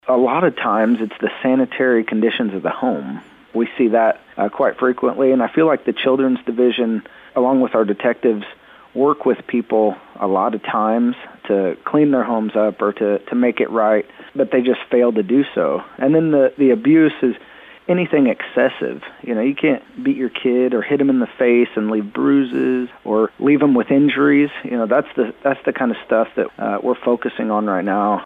Police Chief John Maples explains the conditions they see in these types of investigations.